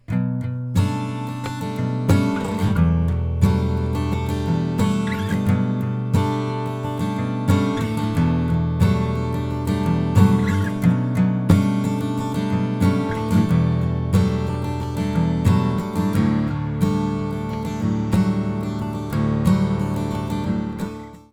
Violet Designin Black Knight olisi tuota hintaluokkaa, ääninäytettä akustisen kitaran soitosta ko. mikillä:
Noissa sämpleissä käytetään muuten eri etuasteita, erona mikrofonimuuntaja ja opari, saundi on aika erilainen jos verrataan toisiinsa.